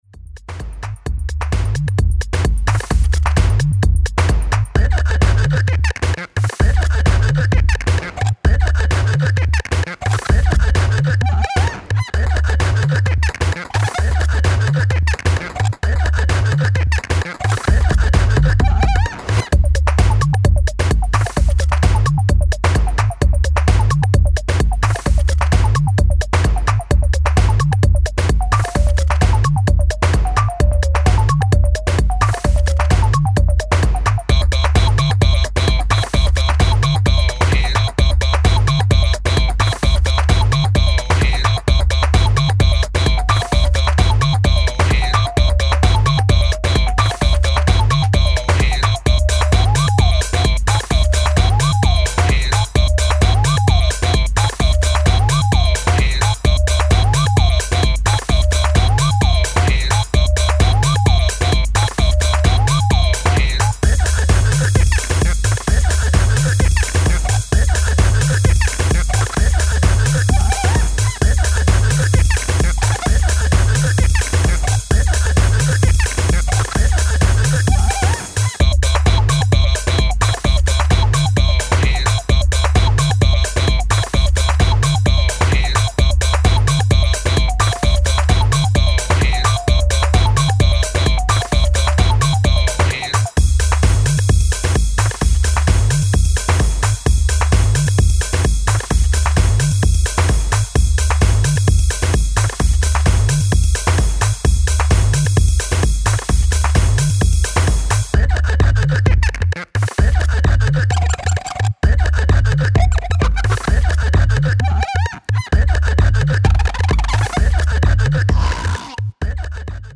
i like this a lot, really funky stuff there mate.